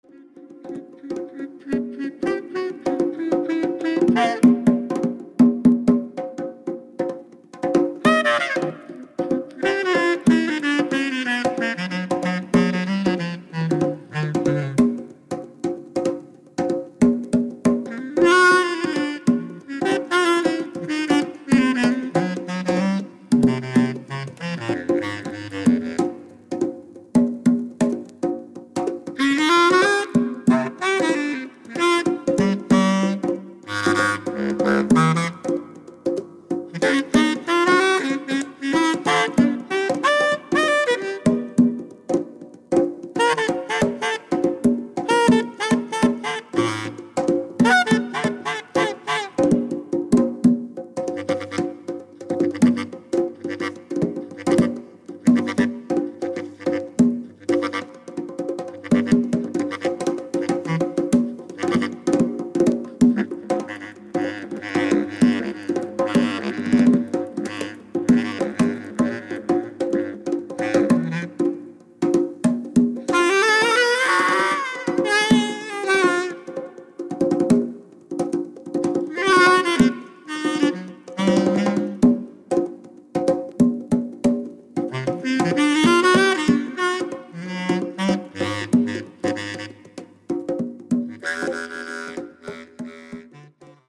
幻想的なアルバムです！！！
Drums, Congas, Electric Piano
Saxophone, Clarinet, Synthesizer